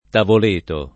[ tavol % to ]